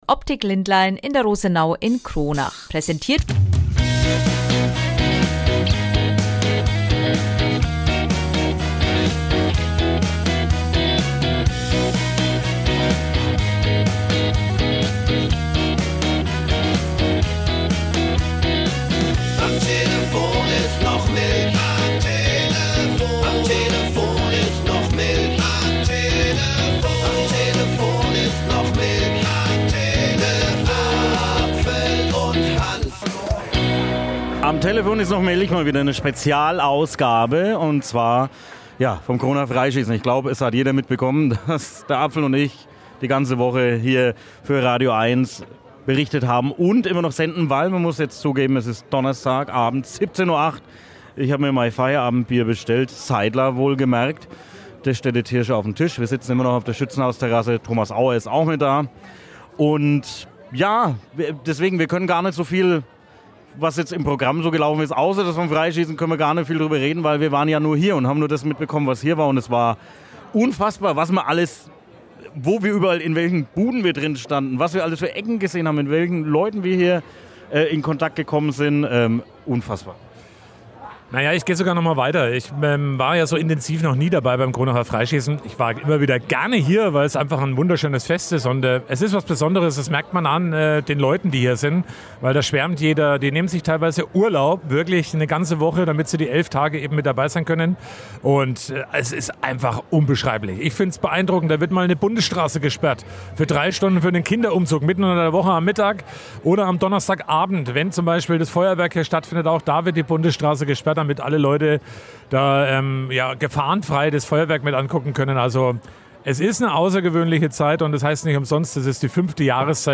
In dieser Podcastfolge gibt es nochmal einige Highlights zum Genießen.